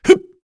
Lusikiel-Vox_Attack1_kr.wav